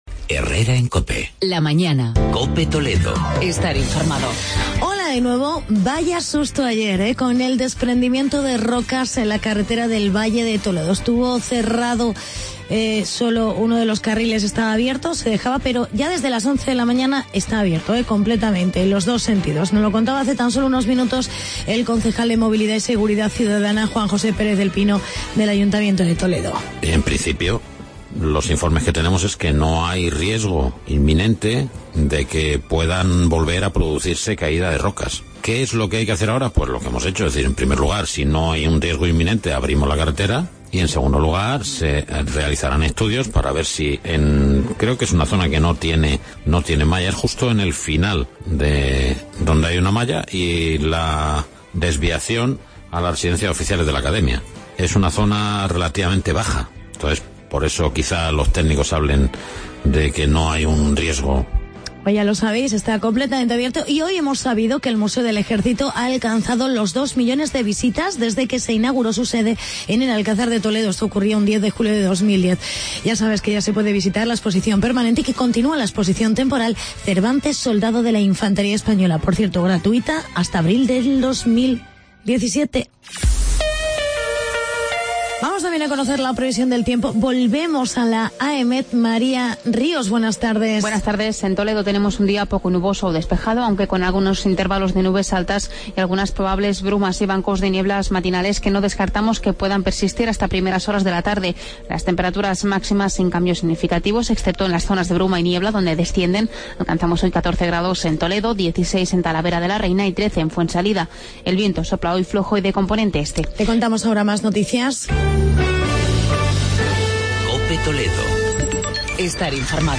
Actualidad y reportaje sobre los Centros de Día para personas con discapacidad.